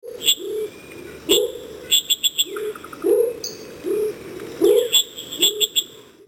دانلود صدای قورباغه درختی از ساعد نیوز با لینک مستقیم و کیفیت بالا
جلوه های صوتی
برچسب: دانلود آهنگ های افکت صوتی انسان و موجودات زنده دانلود آلبوم صدای قورباغه (قور قور) از افکت صوتی انسان و موجودات زنده